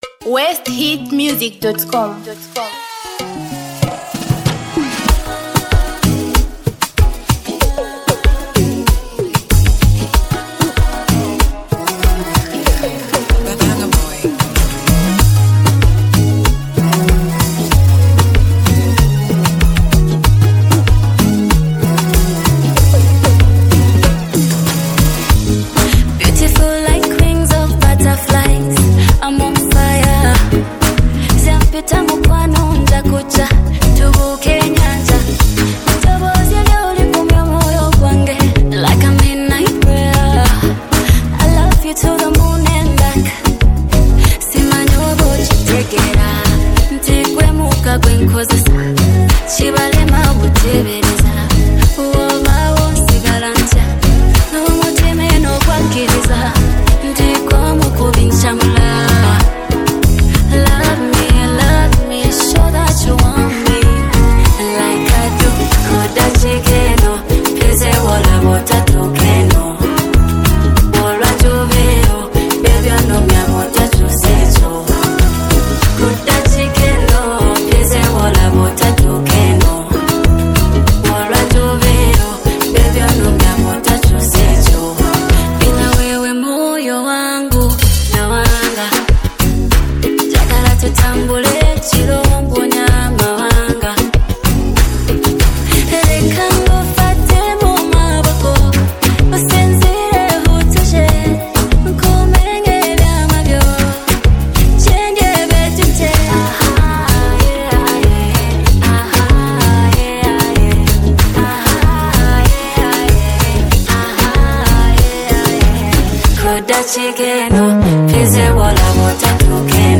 Tanzania Music